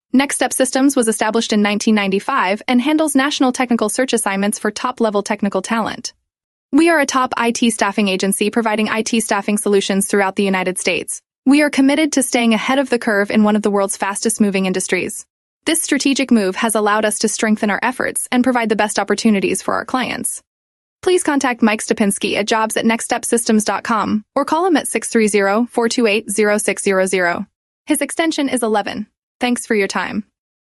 Listen to More About Our IT Staffing Company Using Artificial Intelligence (AI)
Please take a moment to listen to an audio file about our IT staffing company services generated by Artificial Intelligence (AI). Our group of specialized IT recruiters provides staffing services to employers seeking qualified IT professionals and IT candidates seeking employment opportunities.